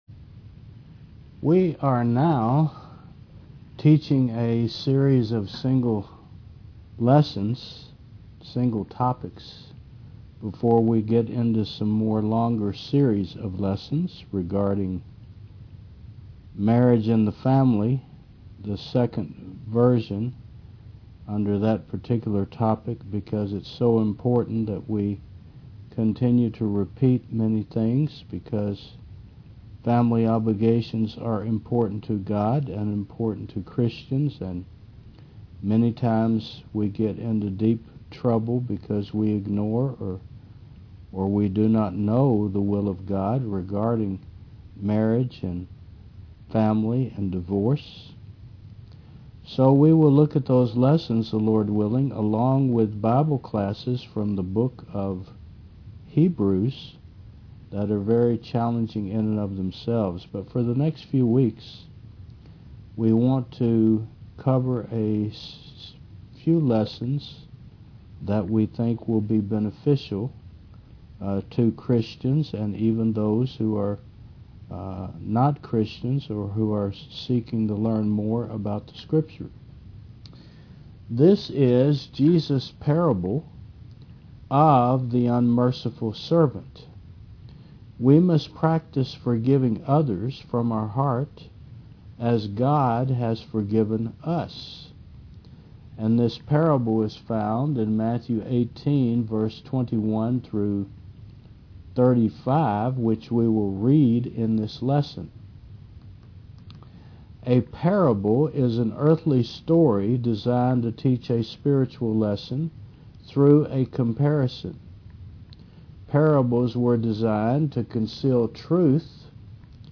Service Type: Tue. 10 AM